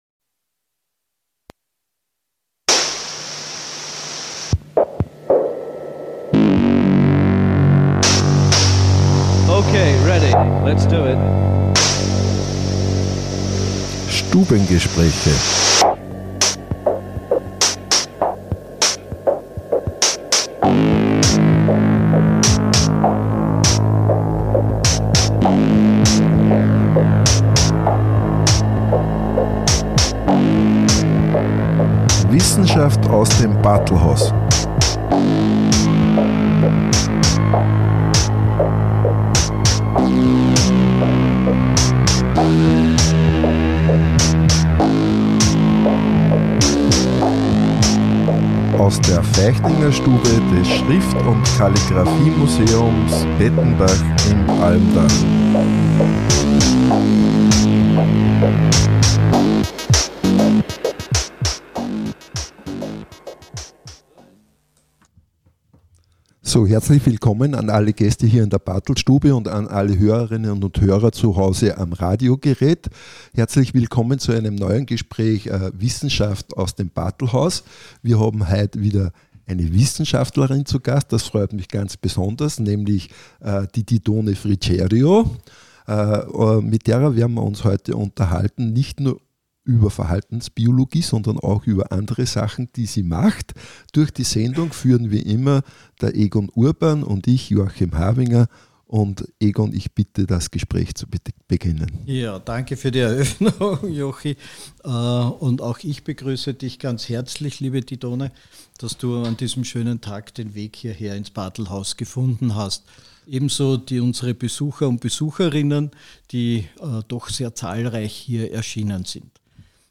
Im Bartlhaus z’Pettenbach, Feichtingerstube